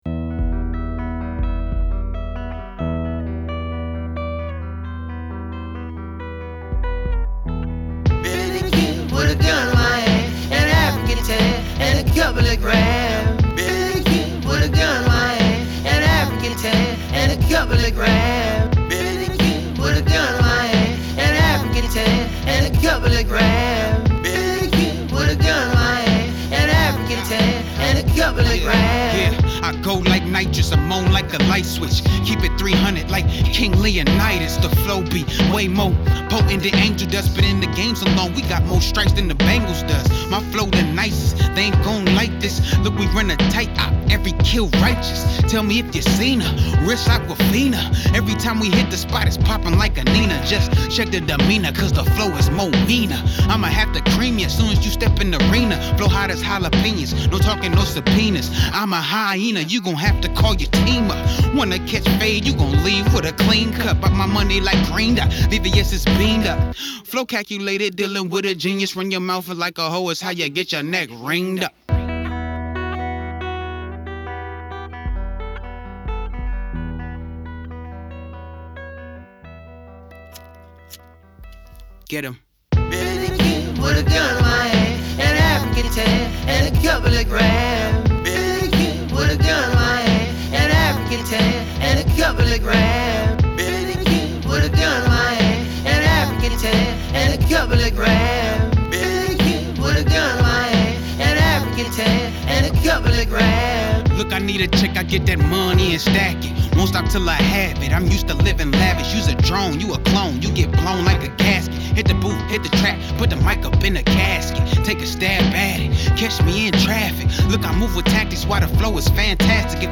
Hiphop
raw hiphop
Guitar